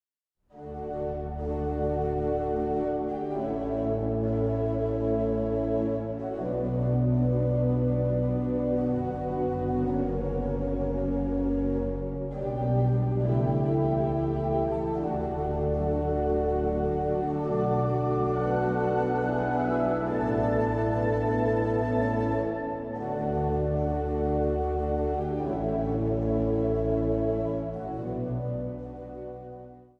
Easy Listening
Instrumentaal | Dwarsfluit
Instrumentaal | Hobo
Instrumentaal | Viool